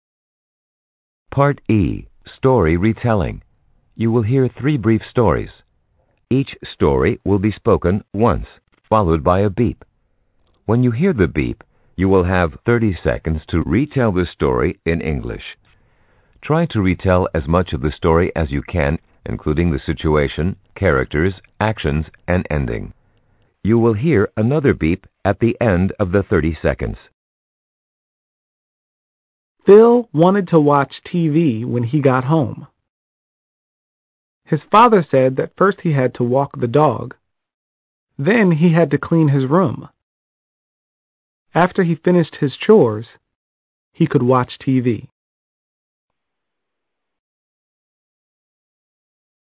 VERSANTのパートEは、問題文として流れてくる25秒前後の英語のストーリーを、30秒でまとめて説明する(リテリングする)テストです。
以下はVERSANT公式サイトにあるサンプル問題。